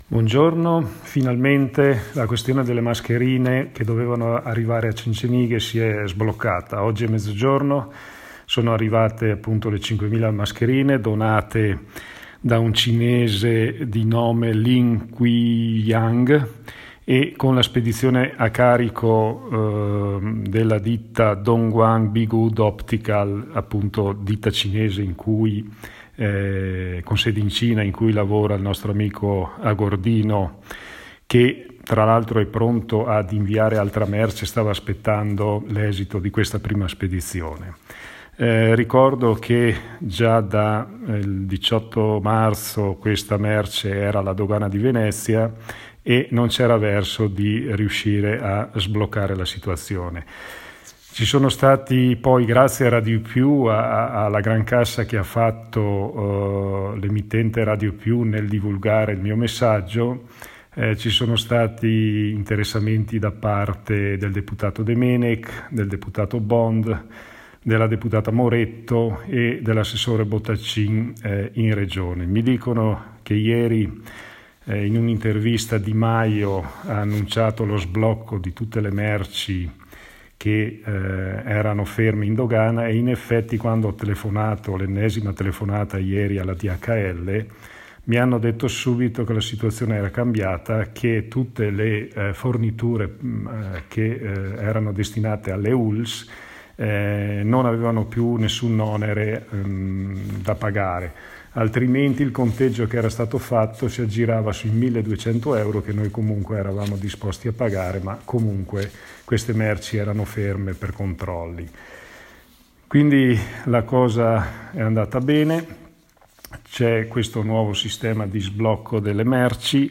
AUDIO, IL SINDACO MAURO SOPPELSA